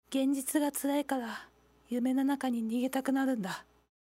女性_「夢だと気づいても、手放したくなかった」
00:00 / 00:00 女性_「夢だと気づいても、手放したくなかった」 作者： カテゴリー タグ： ボイス 女性 高音 ファイルタイプ： mp3 ファイルサイズ： 54 KB ダウンロード 素材倉庫について ボイス 00:00 / 00:00 ダウンロード 女性_「涙が止まらなくても、前に進むしかない。」